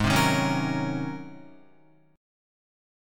G#11 chord